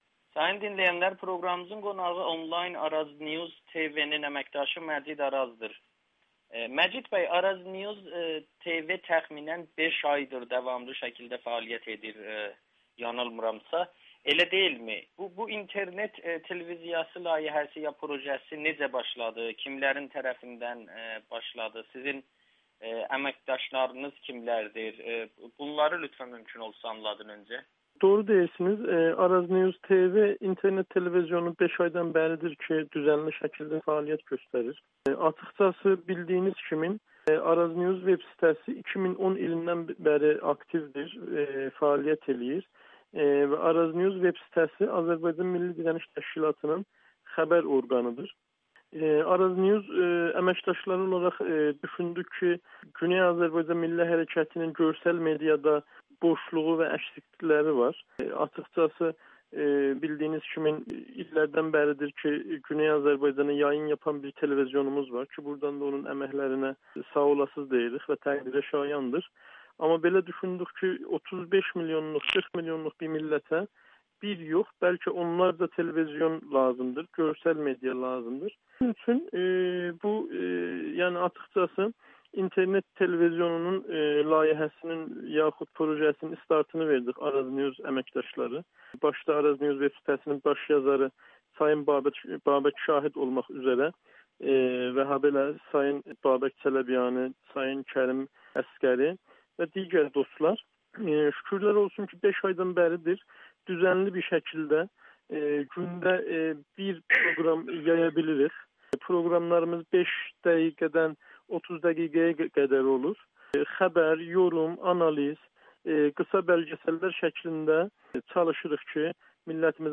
[Audio-Müsahibə]